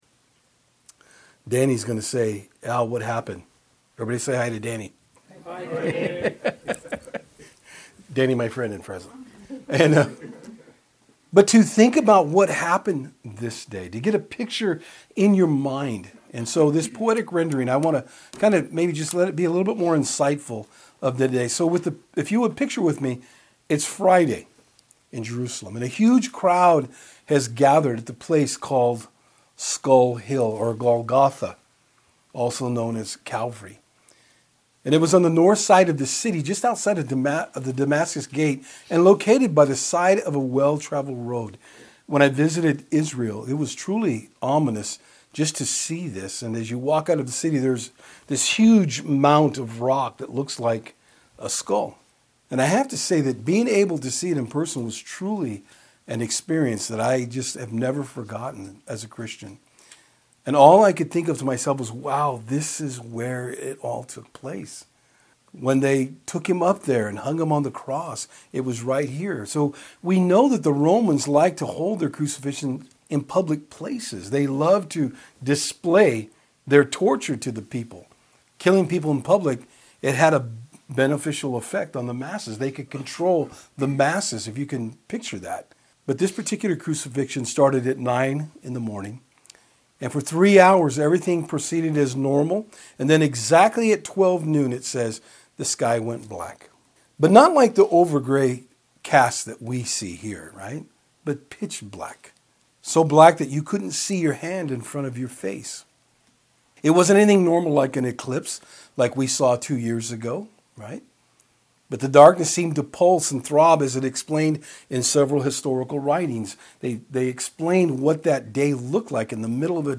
Good-Friday-4-19-19.mp3